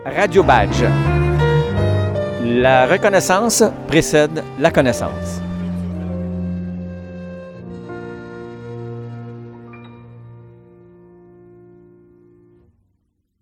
Jingle au Sirop d'érable